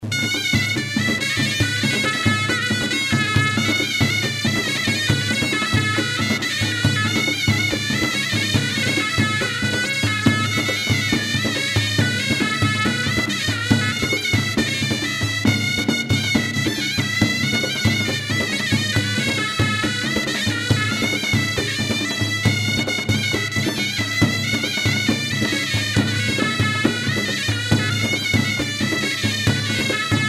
berbere-2.mp3